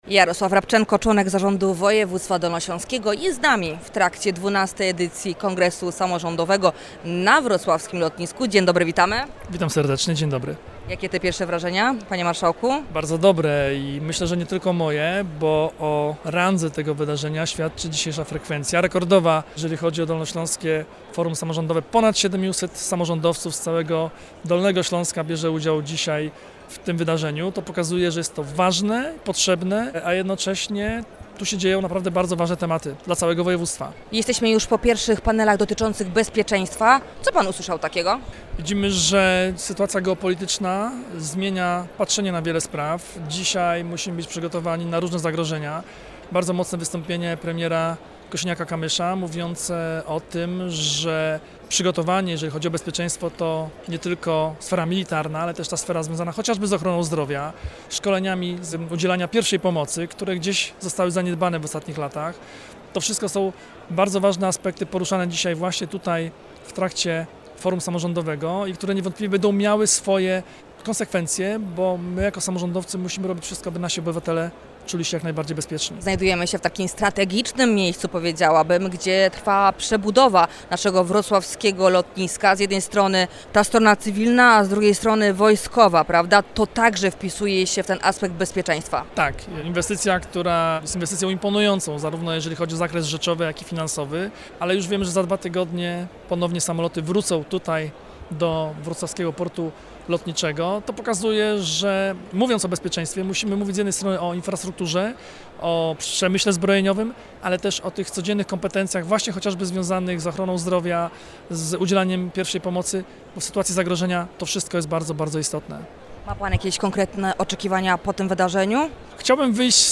Jarosławem Rabczenko – członkiem Zarządu Województwa Dolnośląskiego: